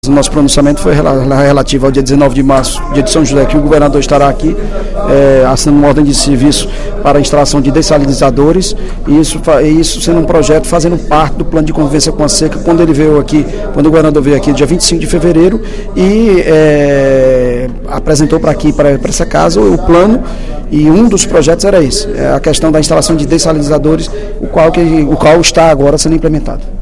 Durante o primeiro expediente da sessão plenária desta quinta-feira (12/03), o líder do governo da Assembleia Legislativa, deputado Evandro Leitão (PDT) informou sobre o encaminhamento das ações emergenciais e estruturantes do Plano de Convivência com a Seca do Governo do Estado ao Ministério da Integração.